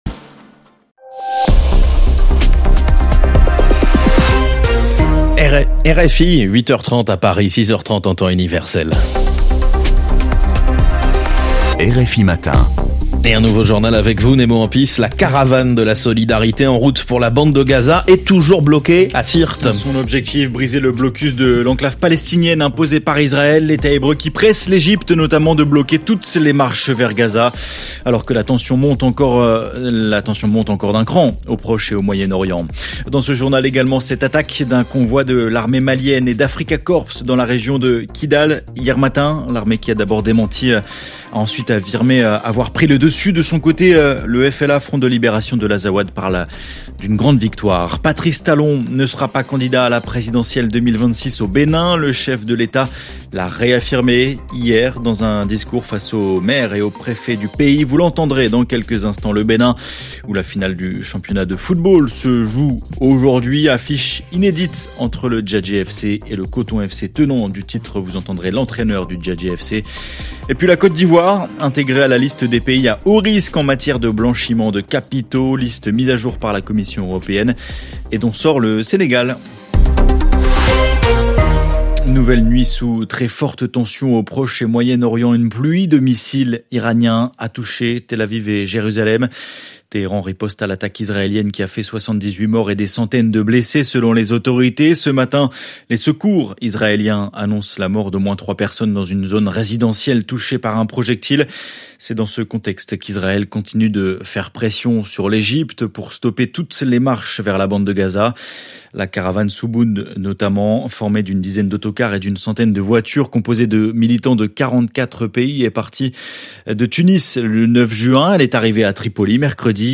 Tranche d'information afrique 14/06 06h44 GMT - 14.06.2025